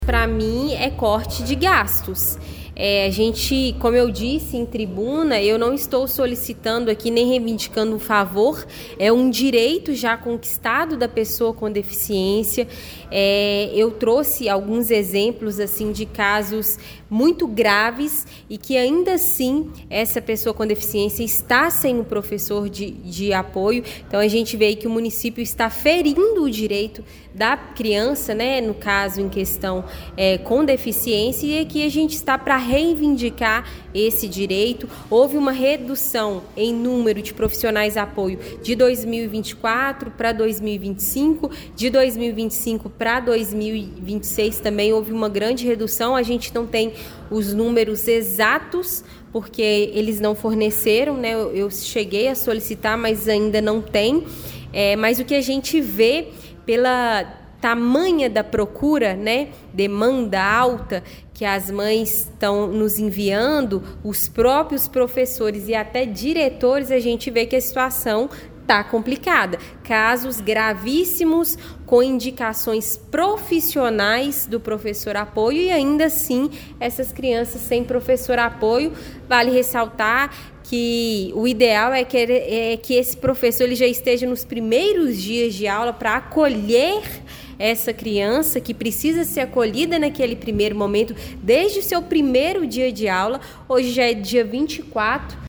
Durante o pronunciamento, a parlamentar apontou que houve redução no número de professores de apoio ao longo dos últimos anos, o que tem agravado a situação.